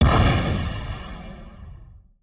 sfx_rocket_launch_2.wav